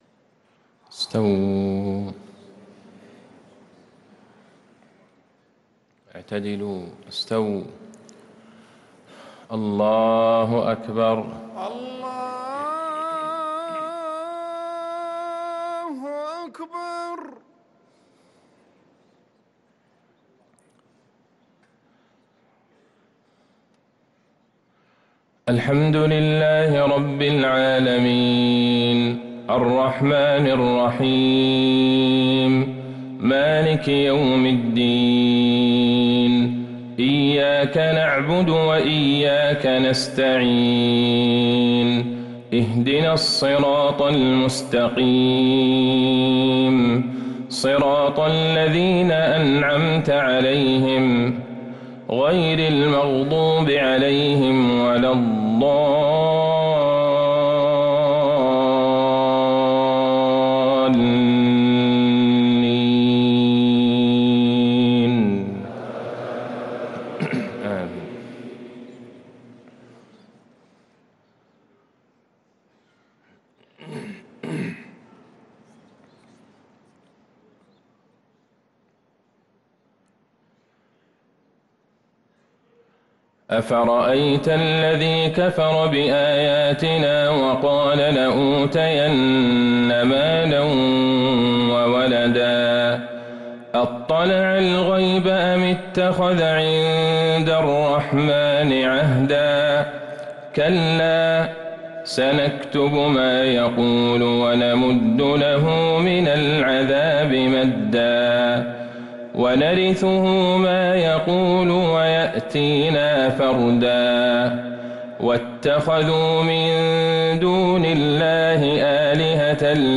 صلاة الفجر للقارئ عبدالله البعيجان 4 ربيع الآخر 1445 هـ